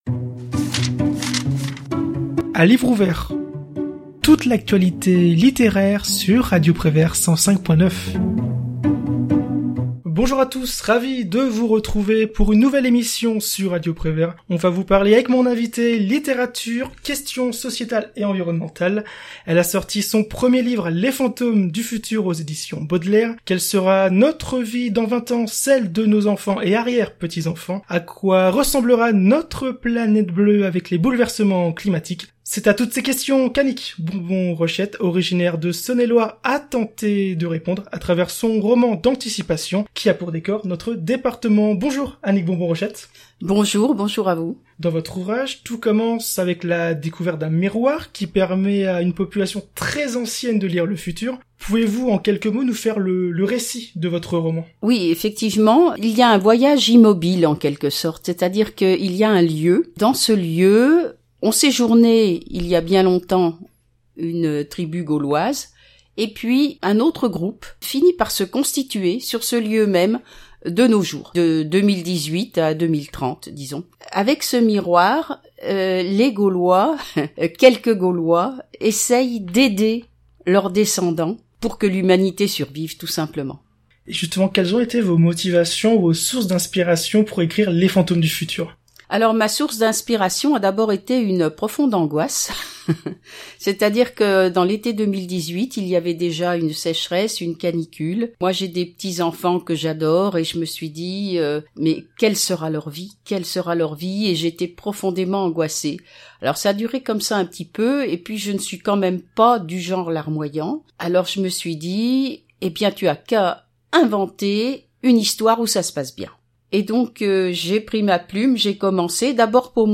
L'Interview de Radio Prévert / Émissions occasionnelles Podcasts